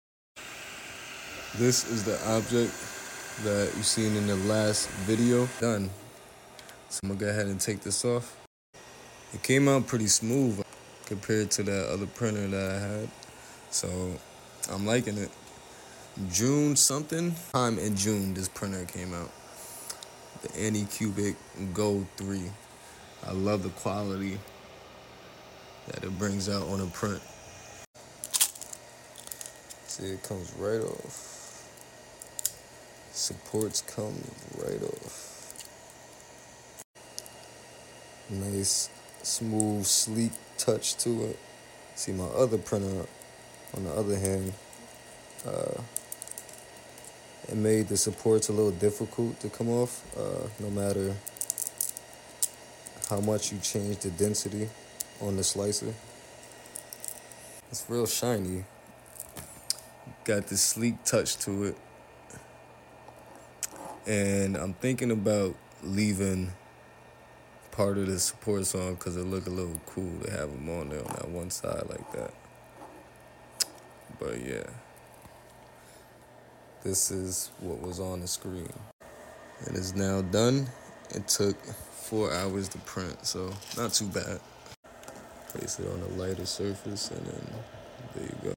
The crunch sound is the best part of the video